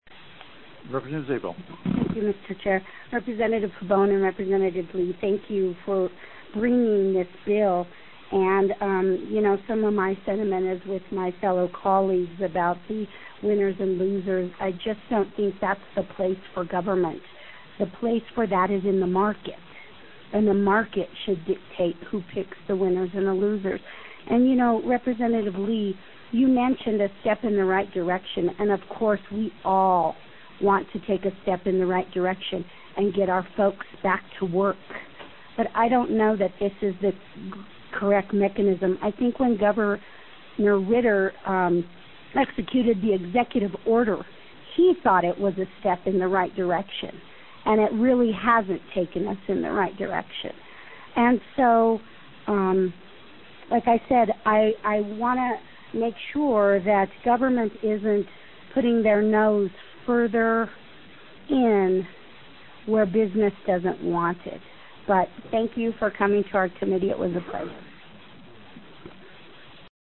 If you’re looking for a good reason why this latest bill was killed by Republicans on the House Finance Committee, though, we’re sorry to tell you you won’t find it in the audio of the hearing.